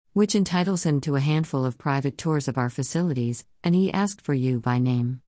それとは対照的にキャロル（国立自然史博物館のディレクター：下記参照）が話す英語はマシンガンのように速いため、聞き取りに苦労しました。
※映画でのCarolが話す英語はテンポが速いため、ヒアリングがちょっと難しいかもしれません。